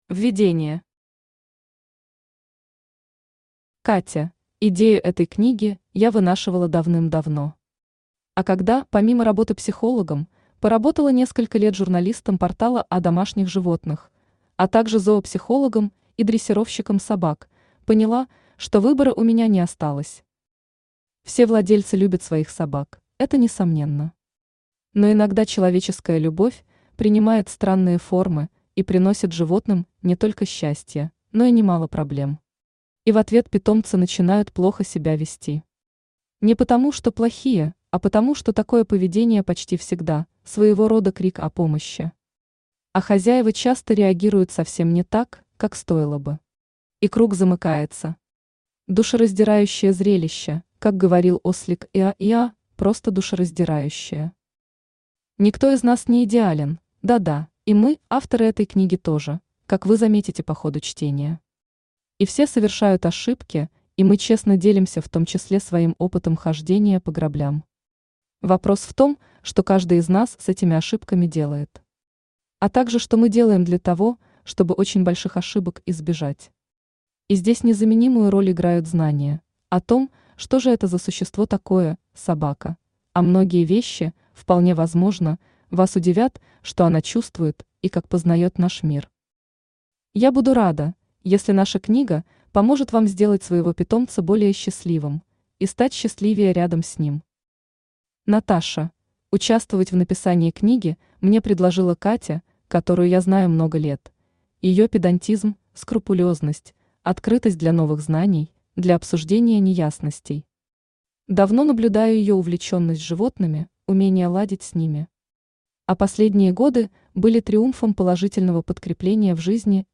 Aудиокнига Хорошая собака плохой породы не бывает Автор Екатерина Кастрицкая Читает аудиокнигу Авточтец ЛитРес.